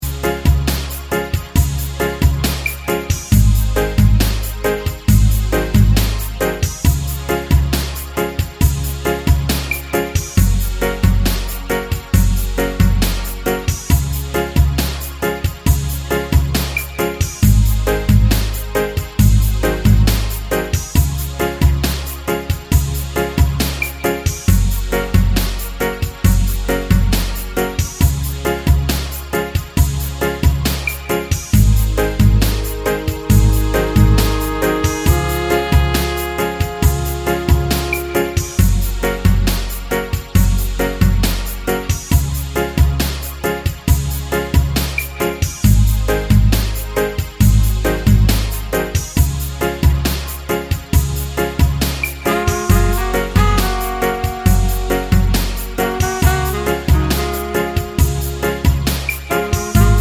no Backing Vocals Reggae 5:03 Buy £1.50